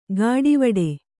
♪ gāḍivaḍe